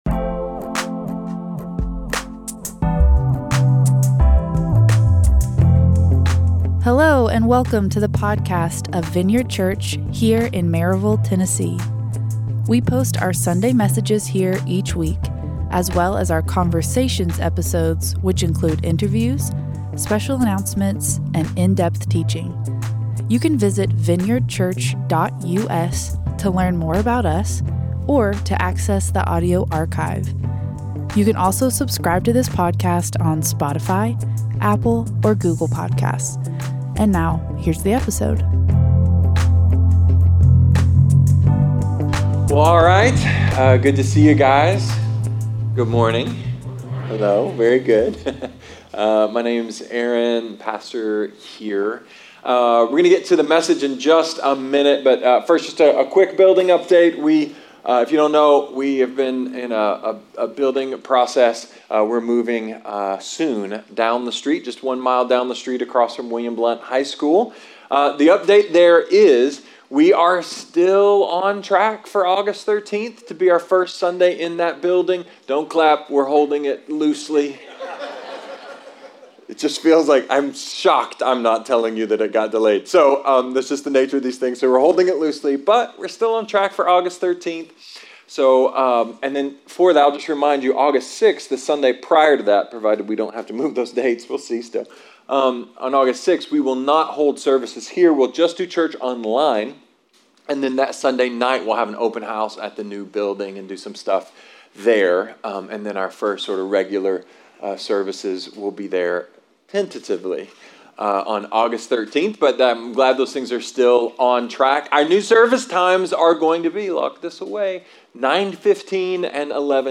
A sermon about promises, the law, Jesus… and Taylor Swift?